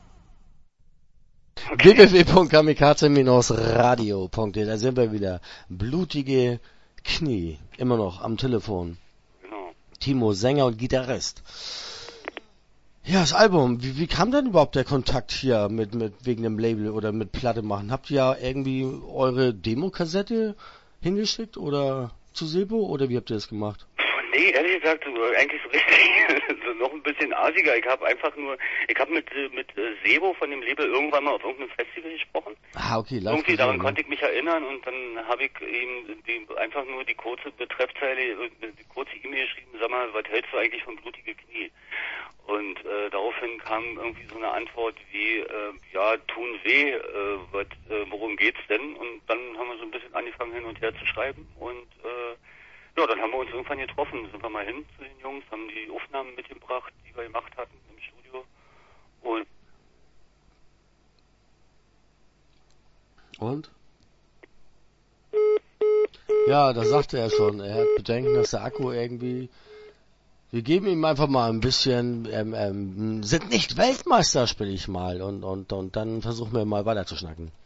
Start » Interviews » Blutige Knie